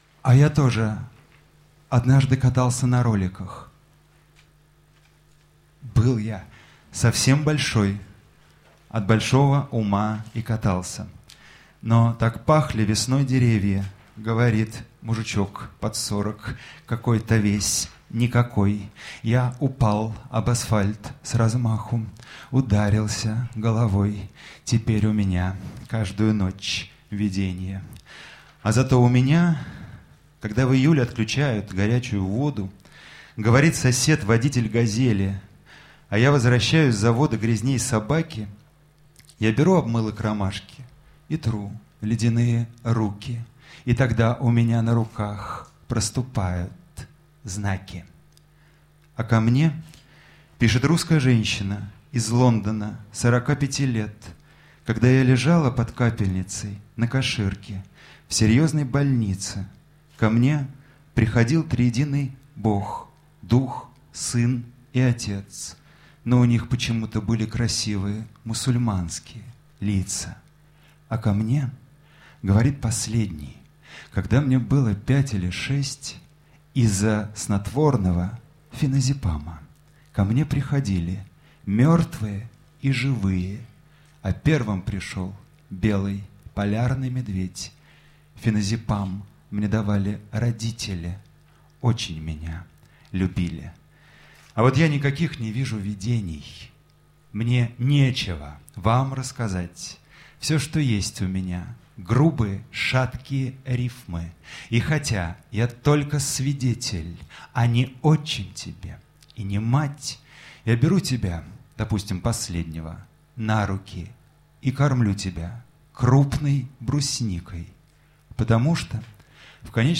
Концерт в «Практике», 2009 год.